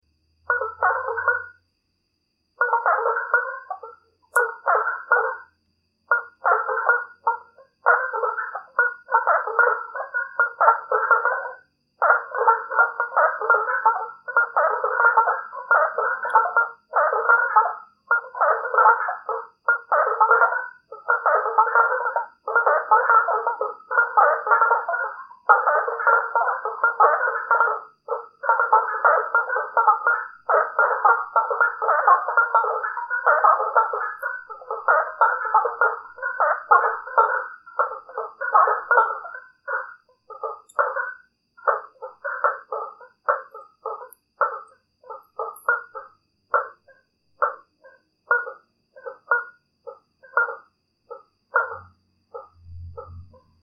Blacksmith tree frogs at the source of a river
Vocalization of Blacksmith tree frog near a river source in the early morning of a full moon, in Votorantim, Brazil.